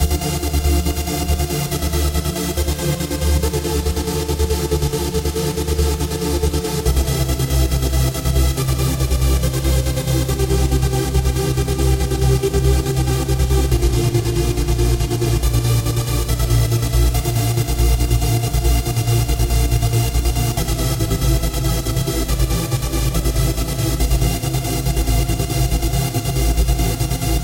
描述：恍惚的锯和垫
Tag: 140 bpm Trance Loops Synth Loops 4.63 MB wav Key : D